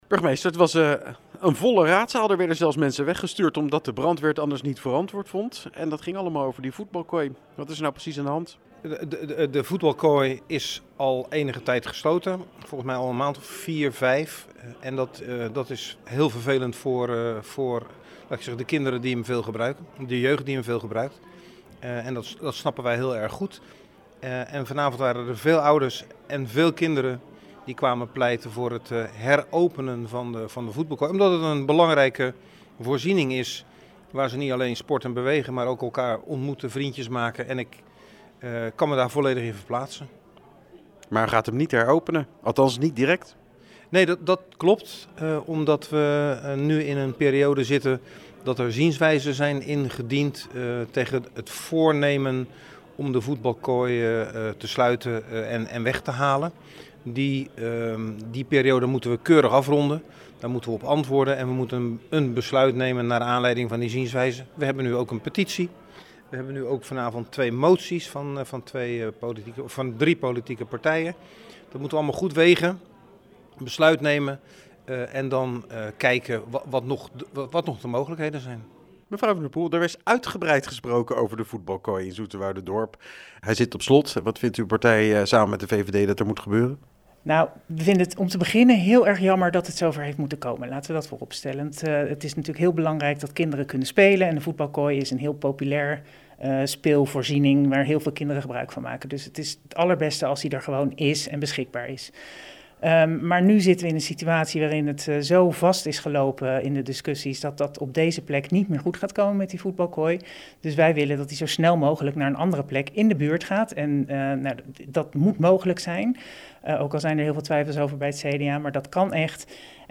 Burgemeester Van Trigt en fractievoorzitters Myrna van der Poel (PZ) en Astrid Coene (CDA) over de gesloten voetbalkooi: